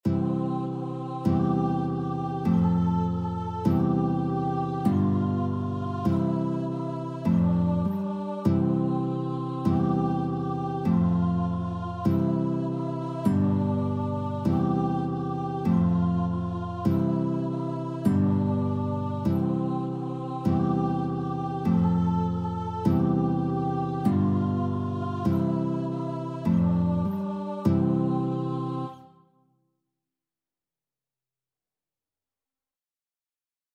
Childrens
Voice
4/4 (View more 4/4 Music)